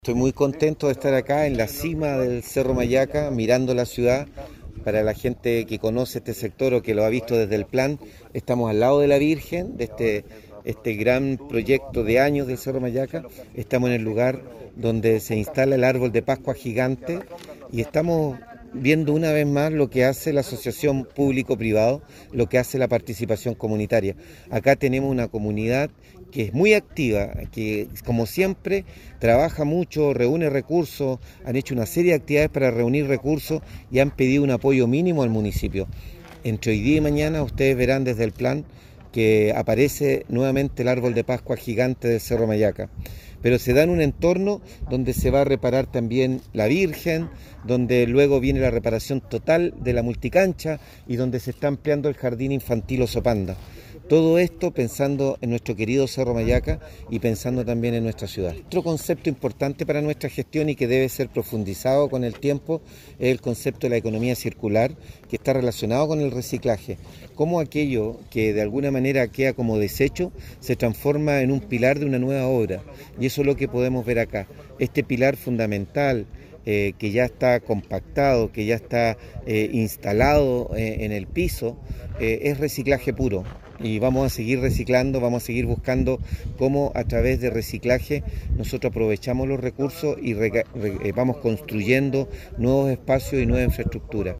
Alcalde-Luis-Mella.mp3